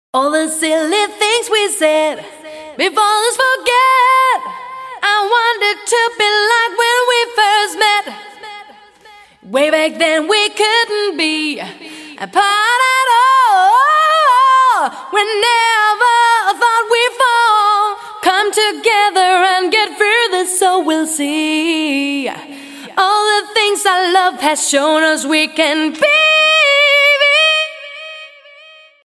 et maintenant la chose avec l'utilisation du compresseur et le pilotage externe par le side-chain....
=> exemple2 c'est quand même beaucoup mieux !!! n'est-ce pas
notez que je n'ai pas changé ni les envois d'aux ni les réglages de reverbes ou delay.... j'ai simplement ajouté le compresseur